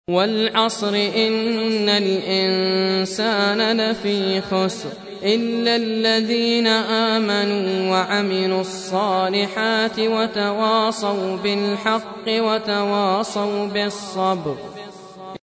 Surah Repeating تكرار السورة Download Surah حمّل السورة Reciting Murattalah Audio for 103. Surah Al-'Asr سورة العصر N.B *Surah Includes Al-Basmalah Reciters Sequents تتابع التلاوات Reciters Repeats تكرار التلاوات